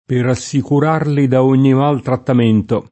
maltrattamento [maltrattam%nto] s. m. — nel senso attenuato di «trattamento cattivo», anche mal trattamento [id.] (col pl. mali trattamenti [m#li trattam%nti]): per assicurarli da ogni mal trattamento [